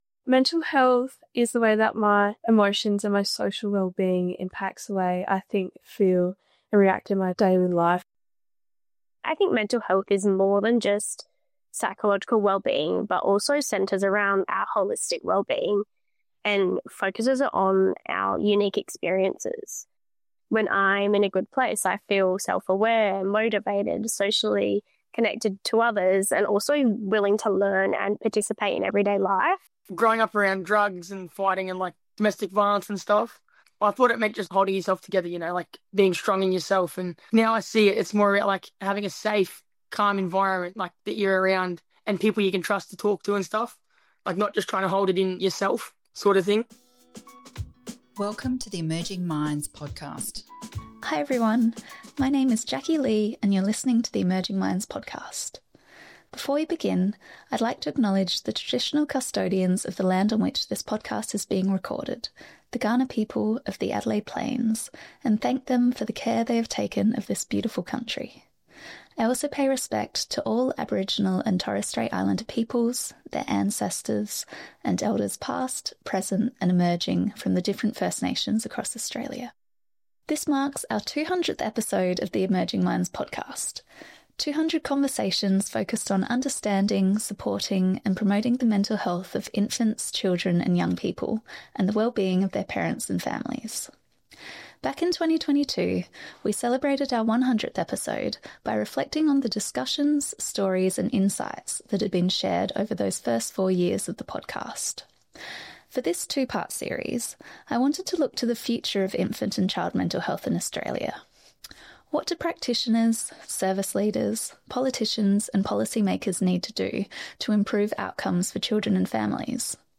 To mark the 200th episode of the Emerging Minds podcast, we wanted to look to the future of infant and child mental health in Australia. Practitioners, researchers, parents and young people discuss the key concerns and priorities for supporting infants’ and children’s mental health, common misconceptions about mental health and wellbeing, and systemic barriers to improving outcomes for children and families.